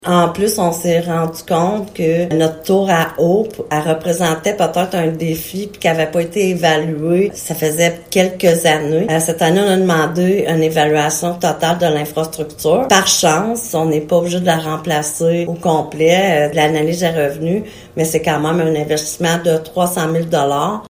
La mairesse de Maniwaki, Francine Fortin, apporte quelques précisions :